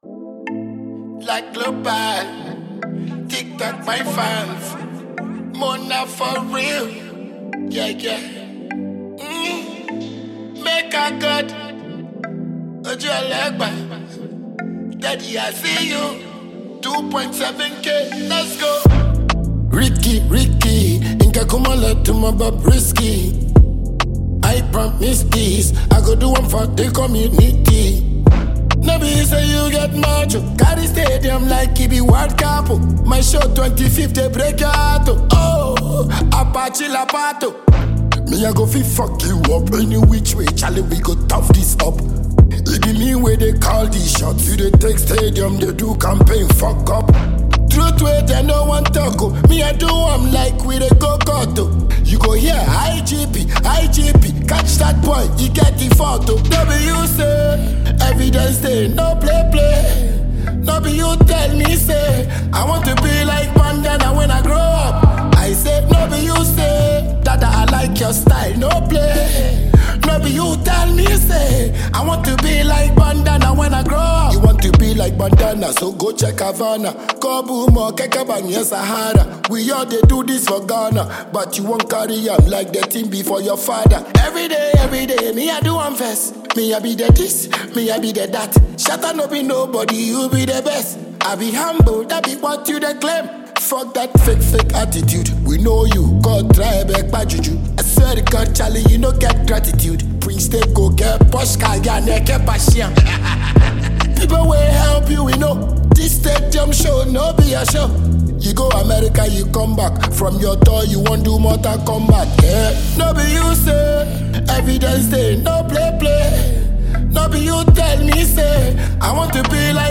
Well renowned Ghanaian reggae-dancehall musician
thrilling new gbedu song